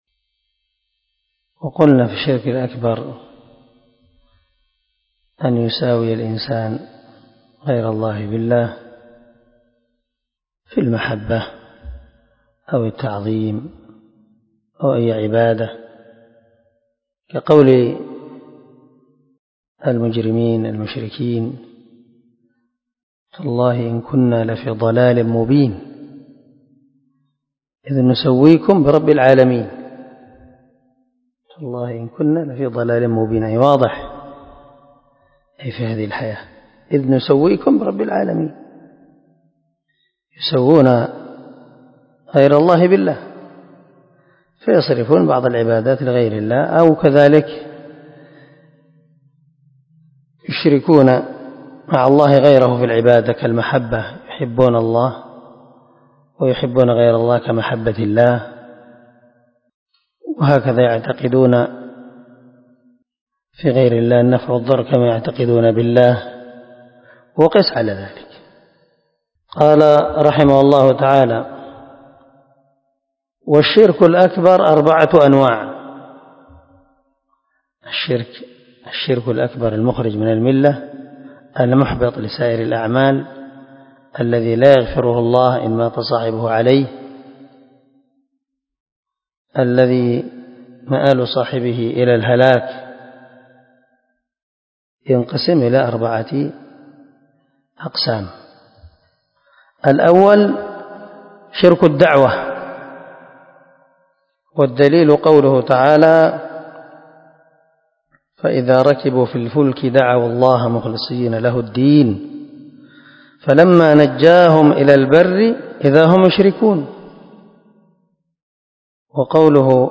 🔊الدرس 25 تابع لأنواع الشرك الأكبر